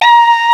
Index of /m8-backup/M8/Samples/FAIRLIGHT CMI IIX/BRASS2
HALFVALV.WAV